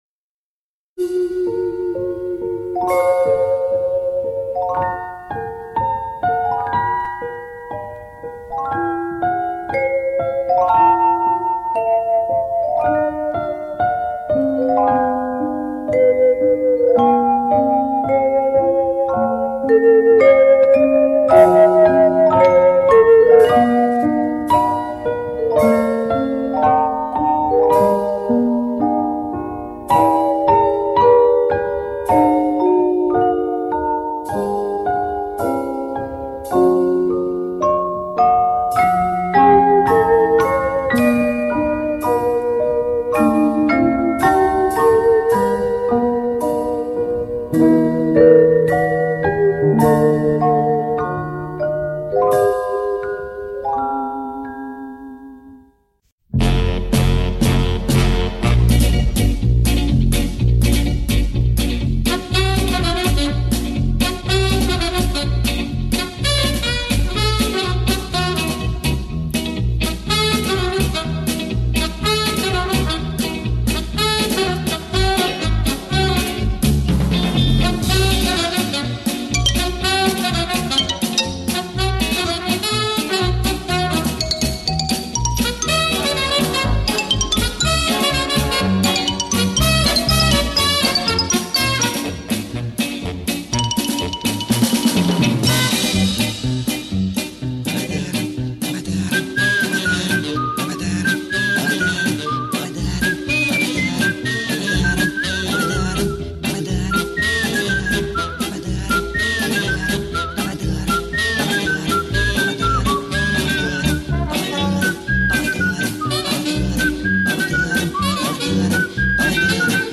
Звуковая заставка фильма